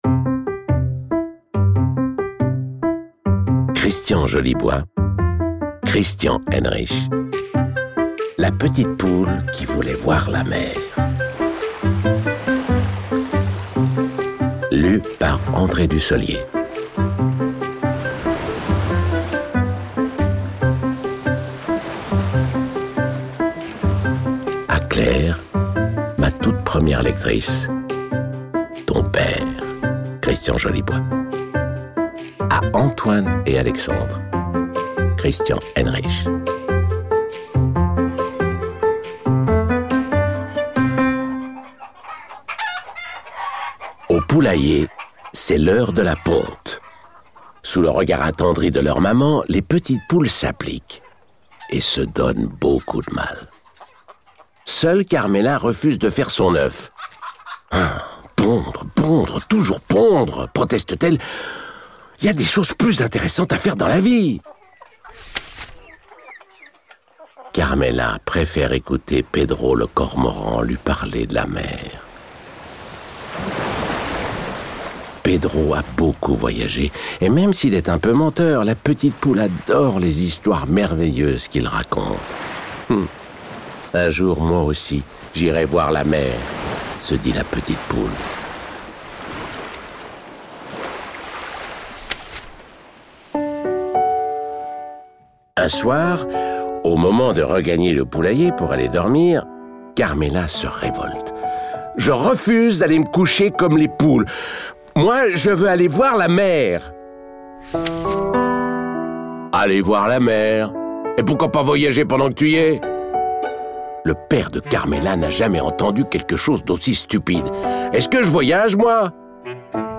La petite poule qui voulait voir la mer lu par André Dussollier... ...un livre audio qui amusera les petits et les grands !